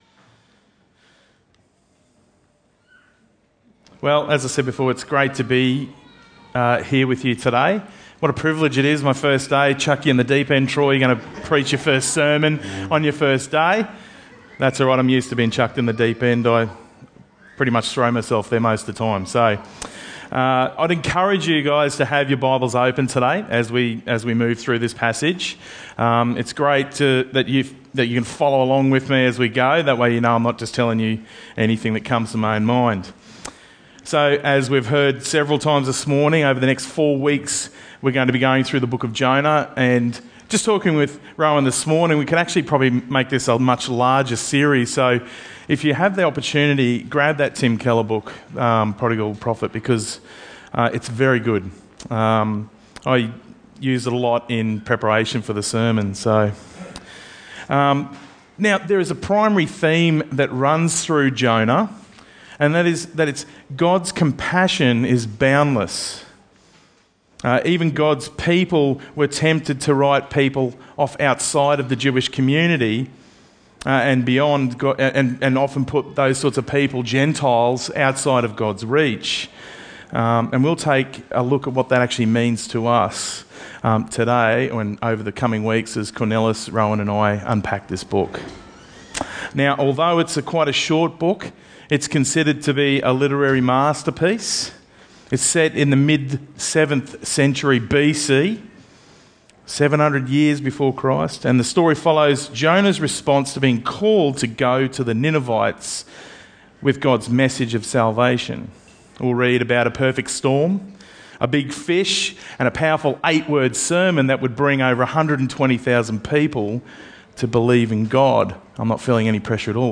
Bible Talks Bible Reading: Jonah 1:1-17